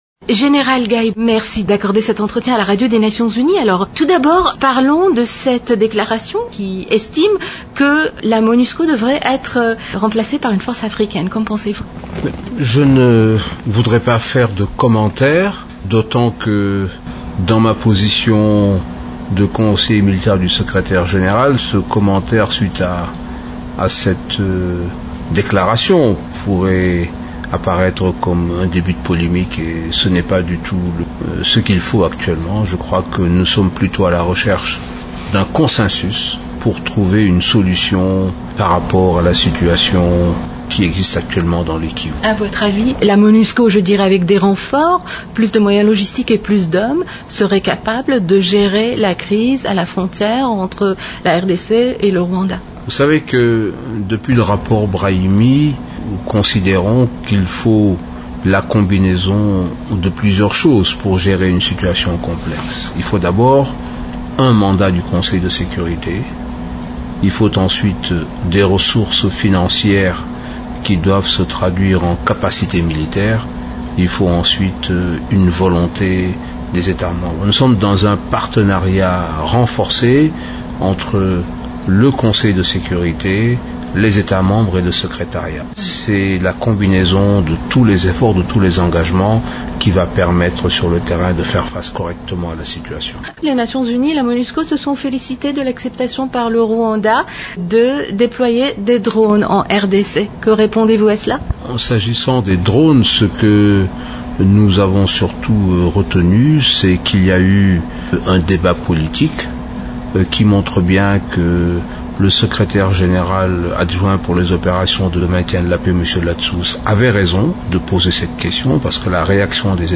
Le général Babacar Gaye, conseiller militaire de Ban Ki-moon, parle de cette « unité d’intervention » et du mandat de la Monusco.